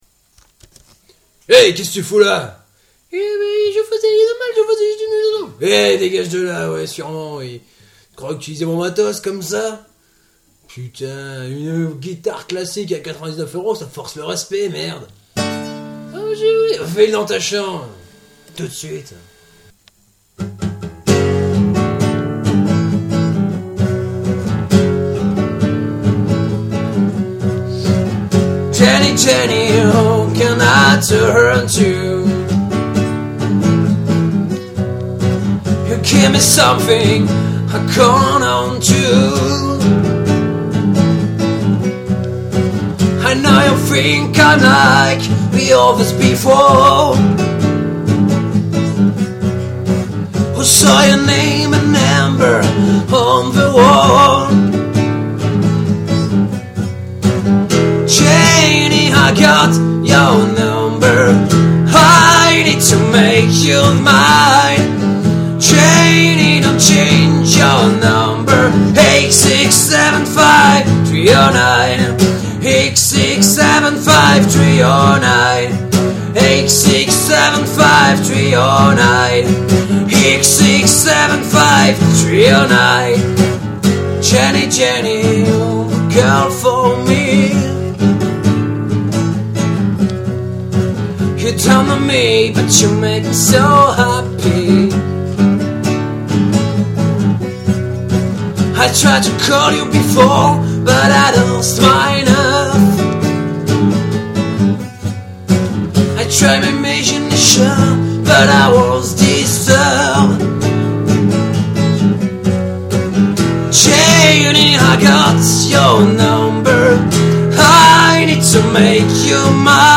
PS : j'ai pas trop appliqué ce que je t'avais dit un peu plus haut, il faut qu'avec le préchorus ça donne pas un impact sonore d'un seul coup, mais bon j'ai essayé un petit crescendo... avec à la clé kk erreurs de chant et de grattes
Franchement j aime bien, la disto est bien en marche
Le petit sketch du debut est bien marrant aussi